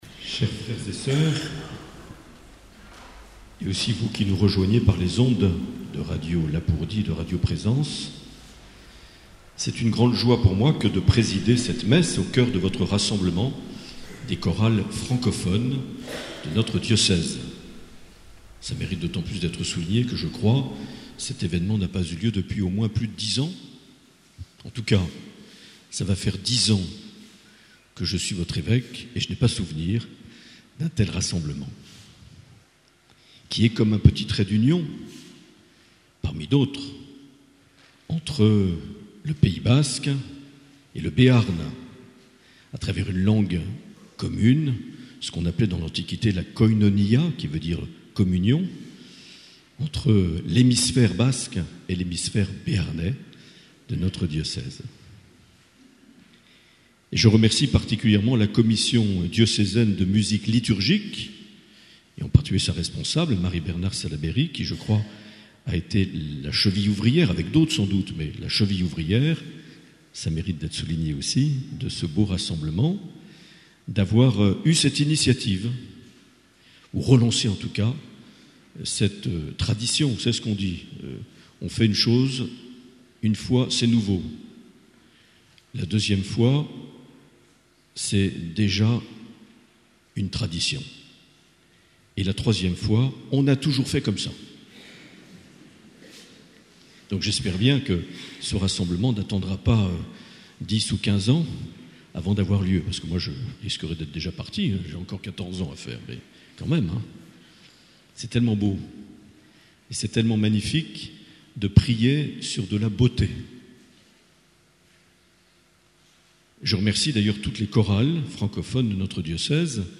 Rassemblement des chorales liturgiques
Les Homélies
Une émission présentée par Monseigneur Marc Aillet